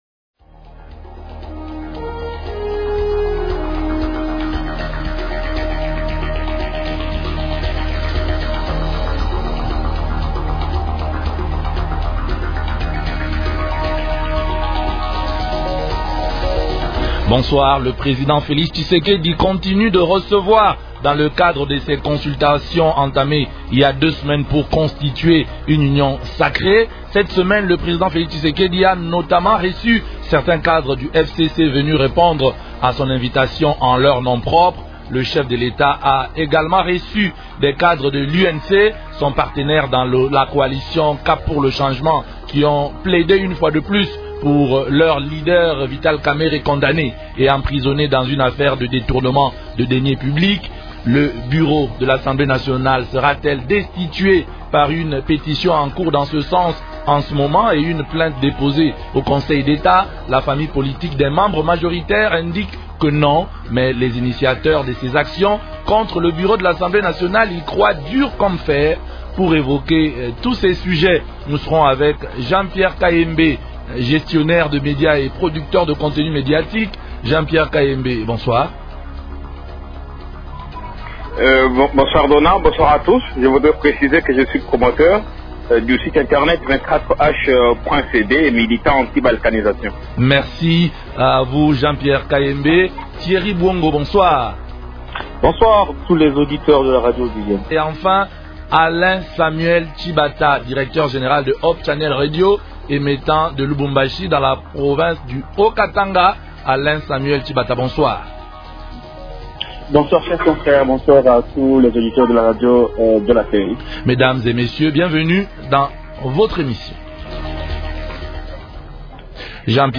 Tribune de la presse. Deux thèmes: Les consultations du président continuent.